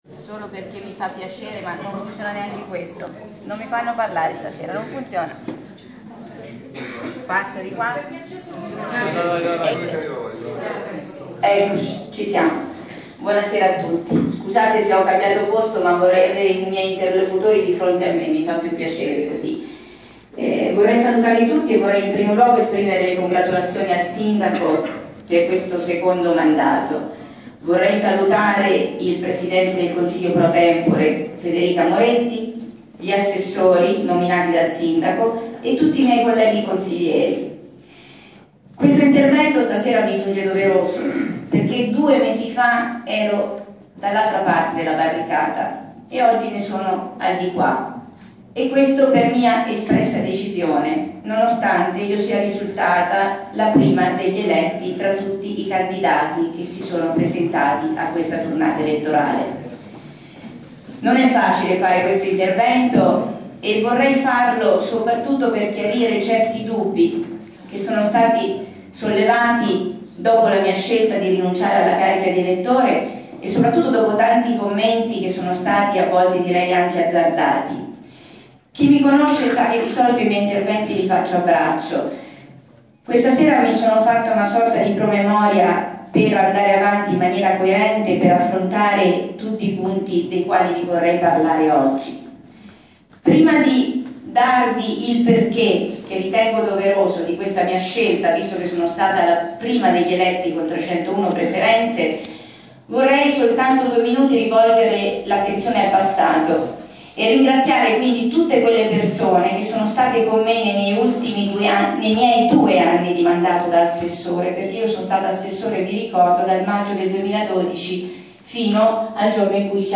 Insediamento del sindaco, l’intervento di Fabrizia Renzini